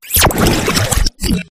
polteageist_ambient.ogg